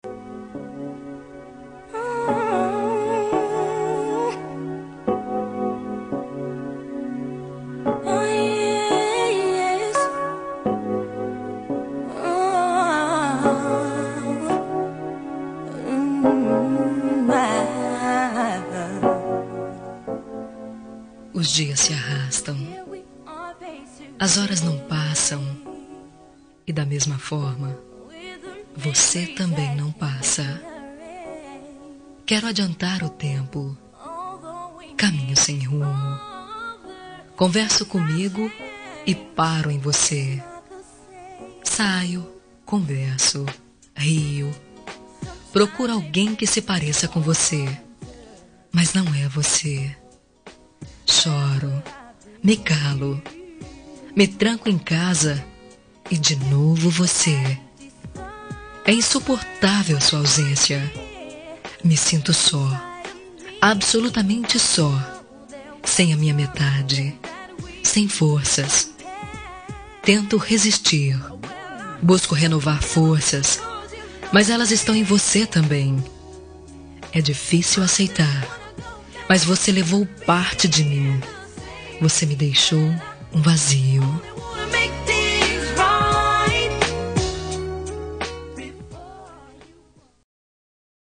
Telemensagem de Saudades – Voz Feminina – Cód: 230025